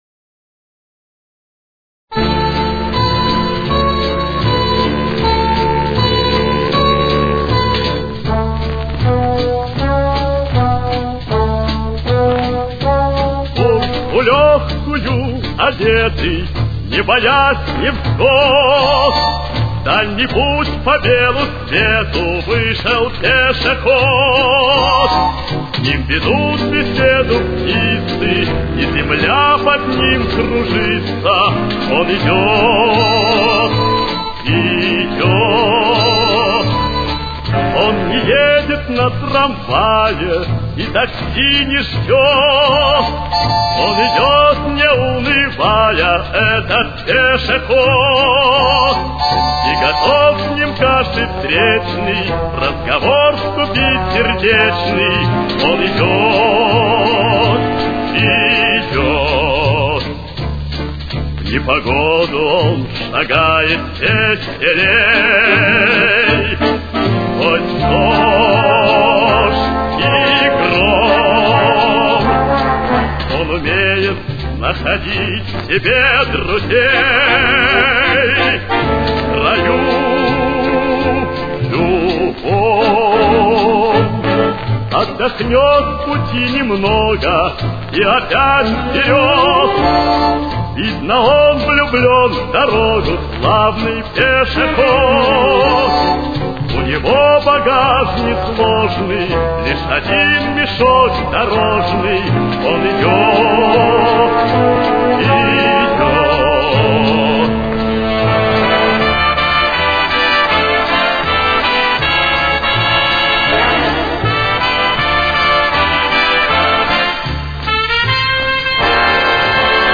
Ре минор. Темп: 81.